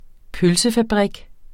pølsefabrik substantiv, fælleskøn Bøjning -ken, -ker, -kerne Udtale Betydninger 1. fabrik der fremstiller pølser vi er den eneste danske pølsefabrik, der saltlagrer den røde spegepølse.